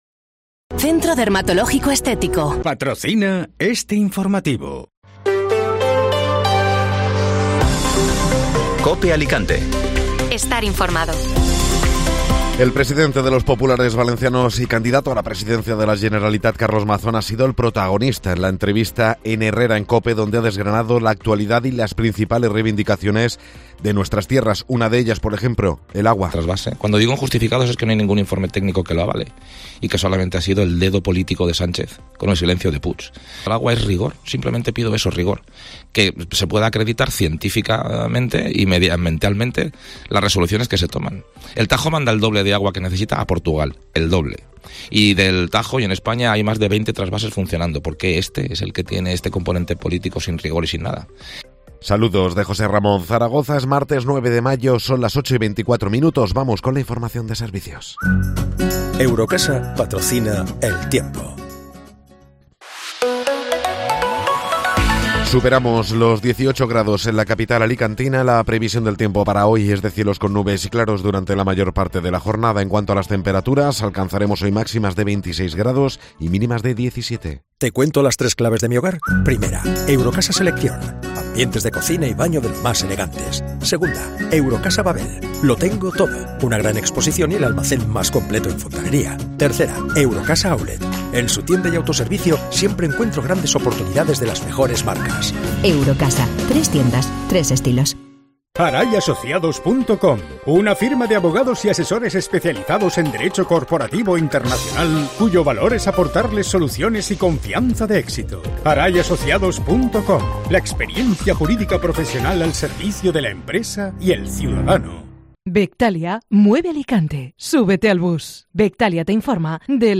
Informativo Matinal (Martes 9 de Mayo)